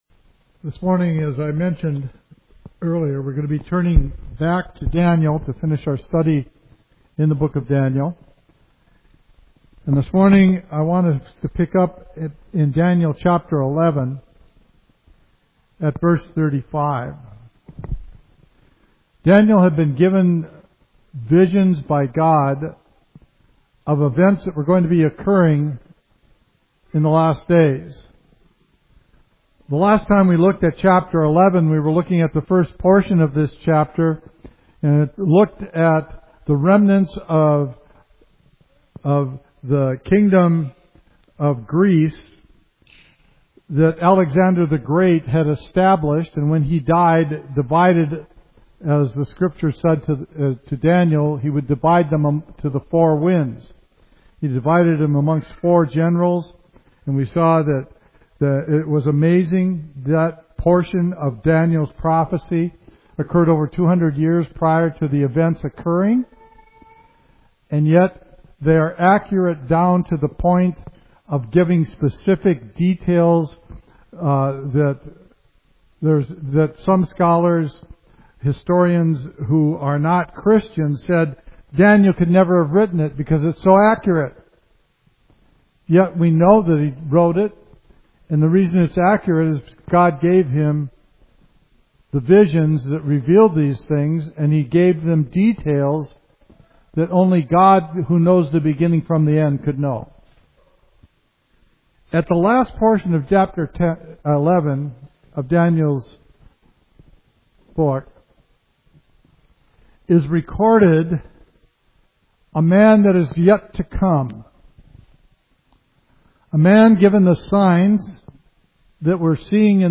Study in Daniel